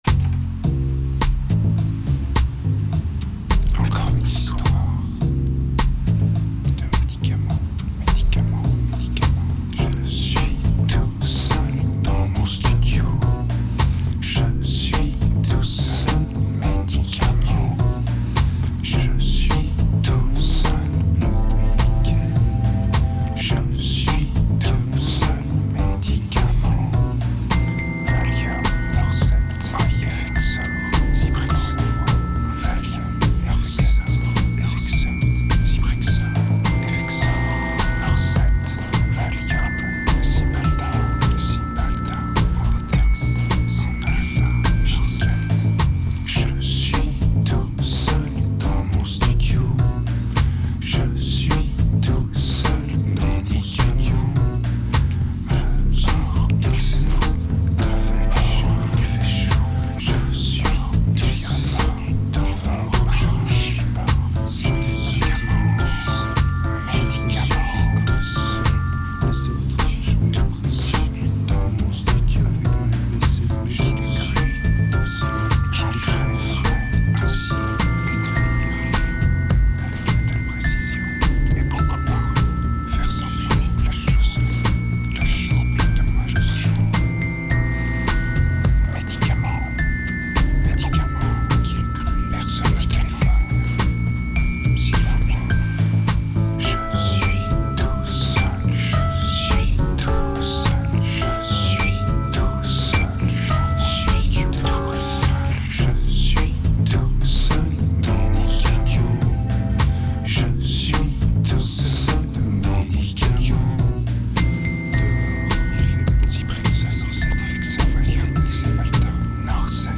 Vocals are still poor but a bit more directed.